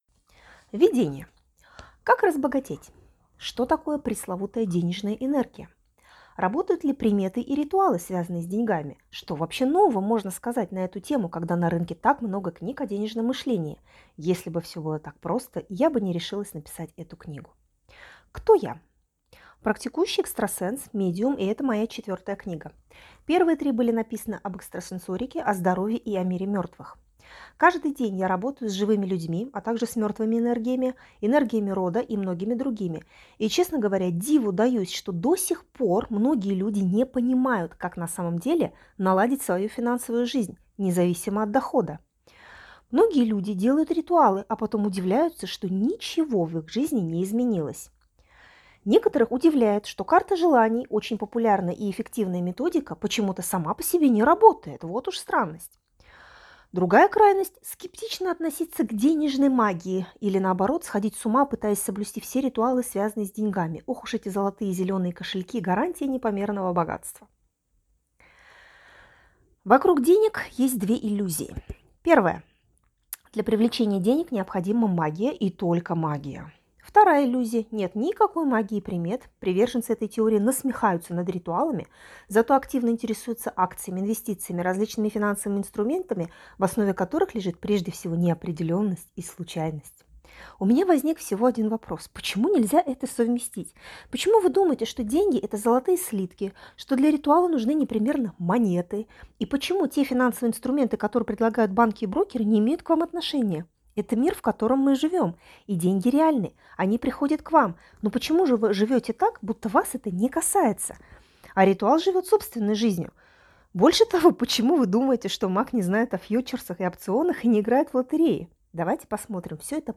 Аудиокнига Пробуждающая энергия денег | Библиотека аудиокниг